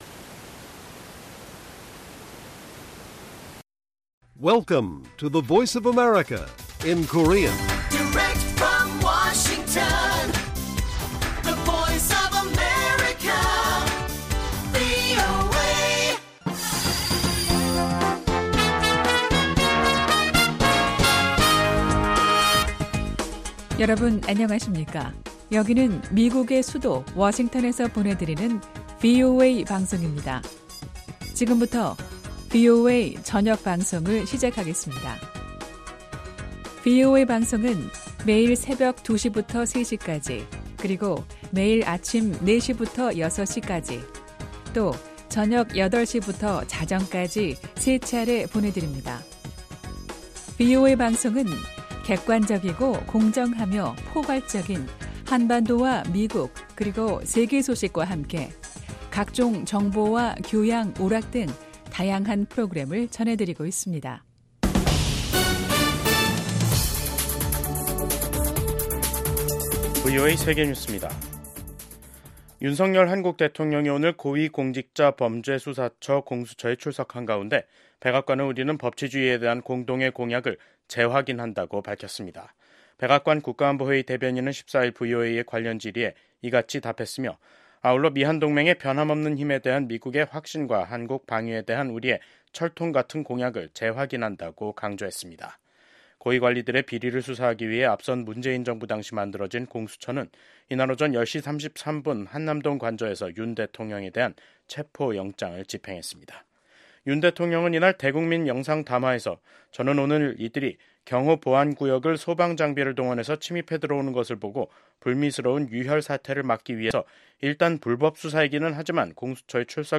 VOA 한국어 간판 뉴스 프로그램 '뉴스 투데이', 2025년 1월 15일 1부 방송입니다. 비상계엄 선포로 내란죄 혐의를 받고 있는 윤석열 대통령이 현직 대통령으론 한국 헌정사상 처음 사법기관에 체포됐습니다. 미국 백악관은 윤석열 한국 대통령이 체포된 데 대해 “미국은 한국 국민에 대한 지지를 확고히 한다”고 밝혔습니다. 미국의 전문가는 트럼프 정부가 혼란 상태에 빠진 한국 정부와 협력하는 것은 어려울 것이라고 전망했습니다.